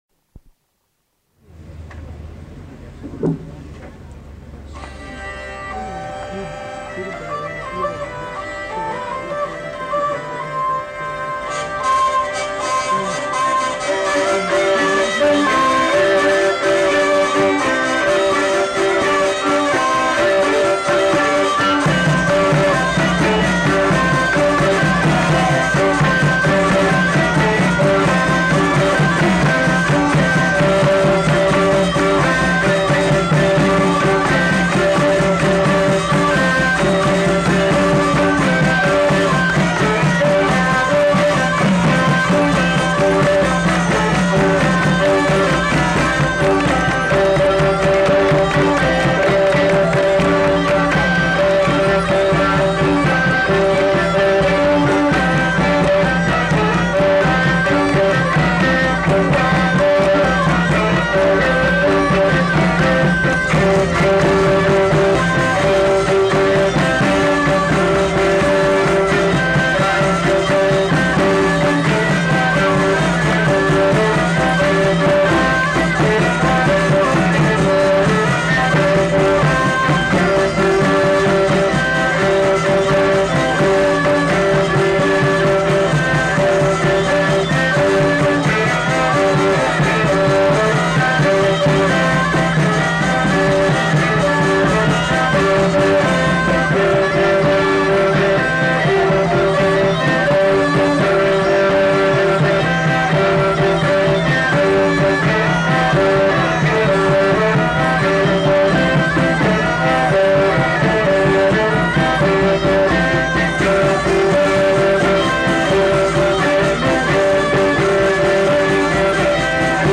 Aire culturelle : Pays d'Oc
Lieu : Pinerolo
Genre : morceau instrumental
Instrument de musique : cabrette ; violon ; percussions ; vielle à roue
Danse : bourrée